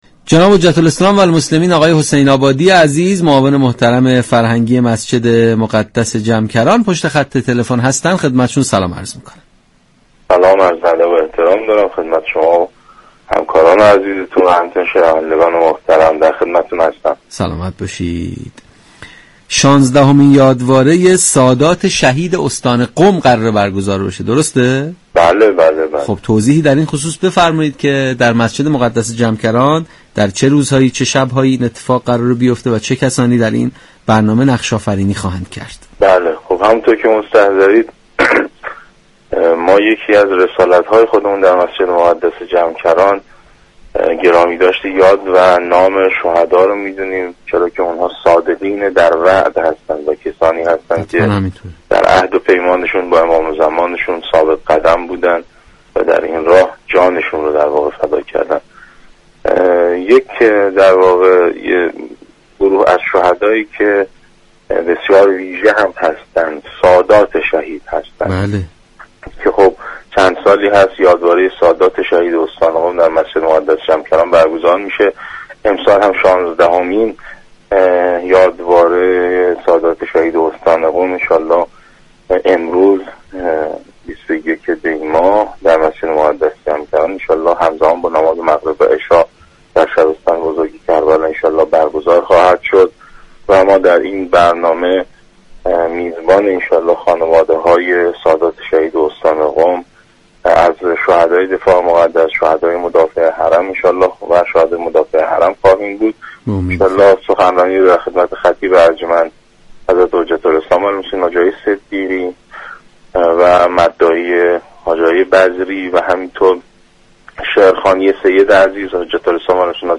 در گفتگو با برنامه تسنیم رادیو قرآن